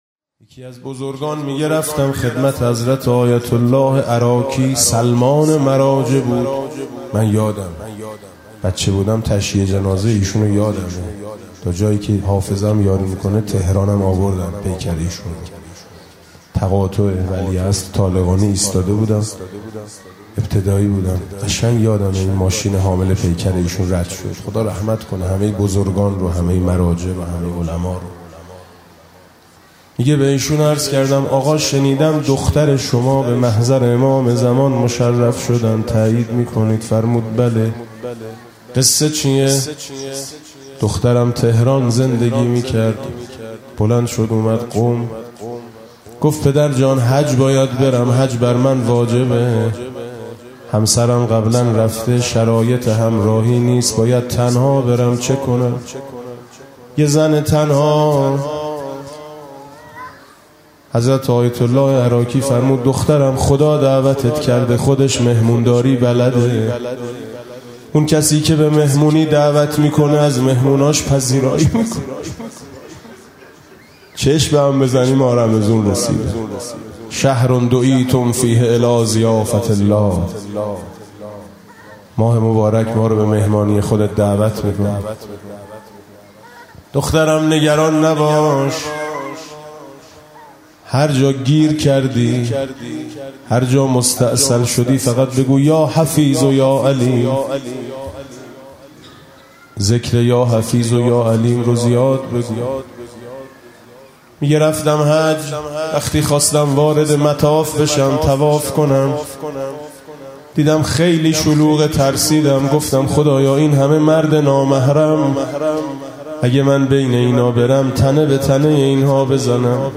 روایت